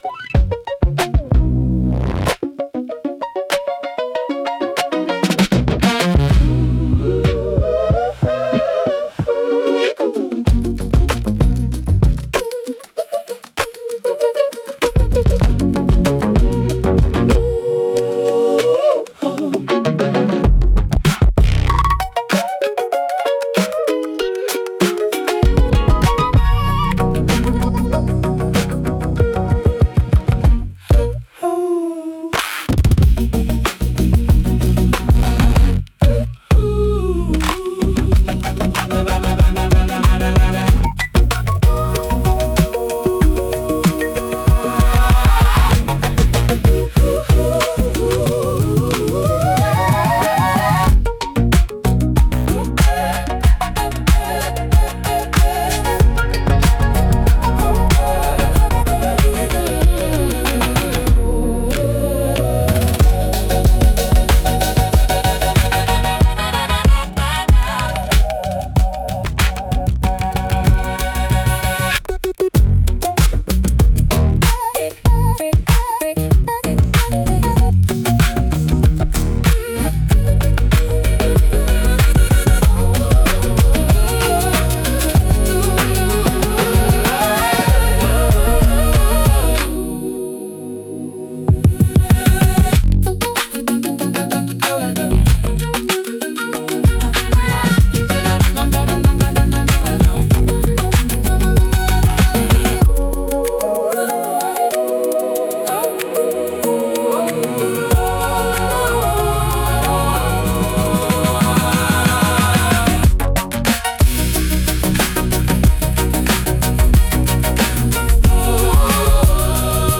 イメージ：インスト,エレクトロ・カーニバル,ローファイ・アートポップ
インストゥルメンタル（instrumental）